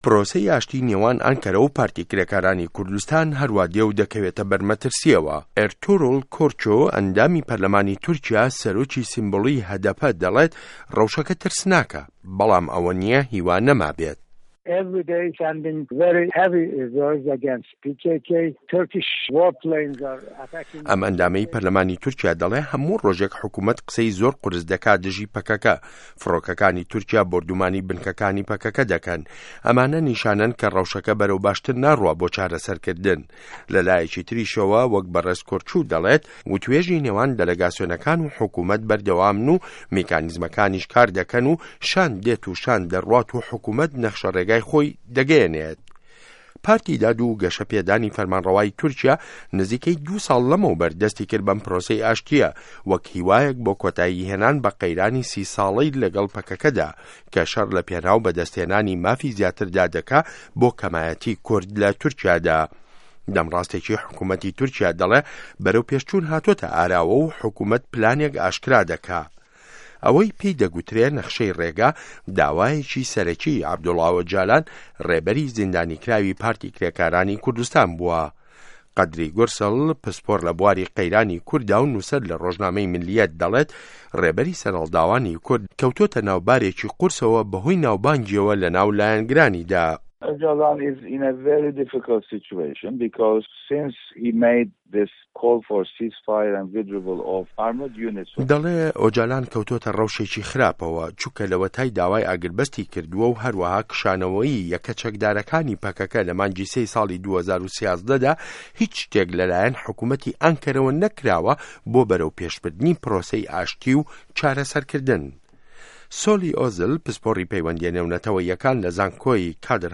ڕاپـۆرتی تورکیا - کورد